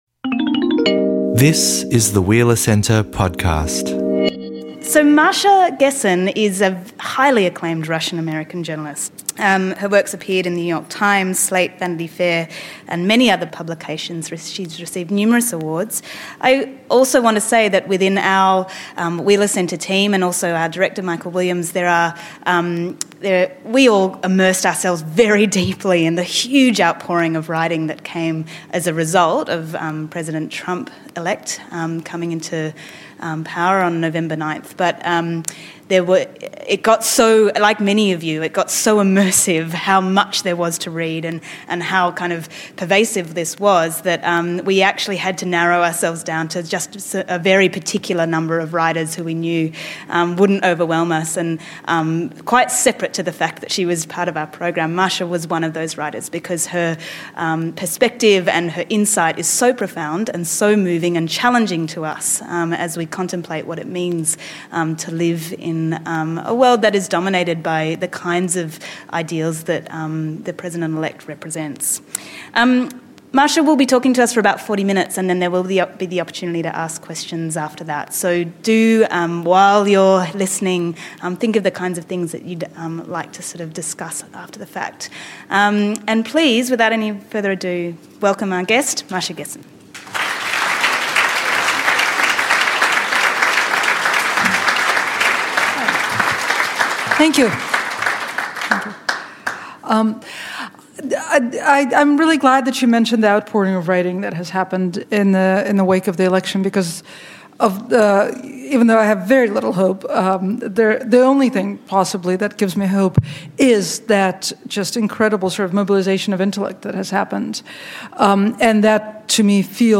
At this Wheeler Centre talk in Bendigo, she discusses the historical, economic and cultural circumstances that have enabled the ascendance of this utterly unorthodox political figure.